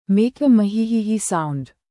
Muhehehe Bouton sonore
muhehehe.mp3